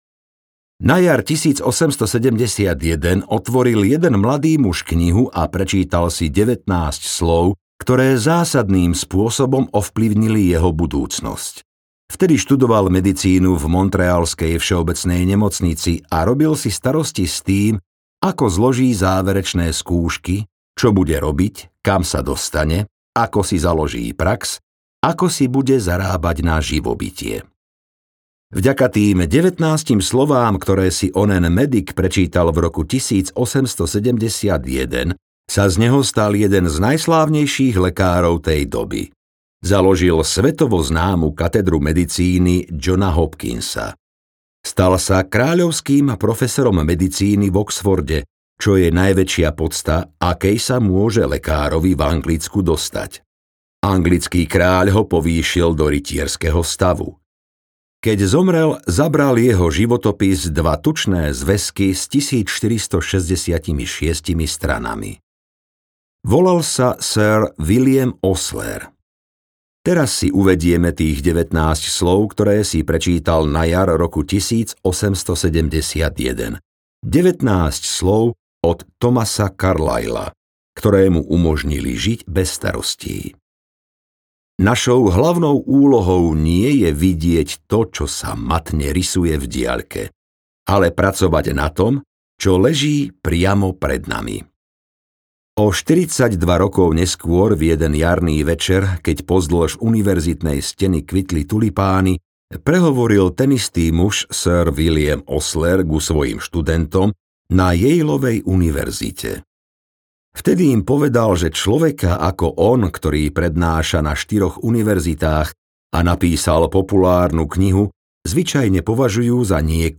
Ukázka z knihy
ako-sa-zbavit-starosti-a-zacat-zit-audiokniha